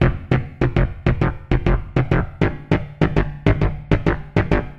Dance music bass loops 2
Dance music bass loop - 100bpm 48